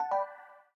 reply_send.ogg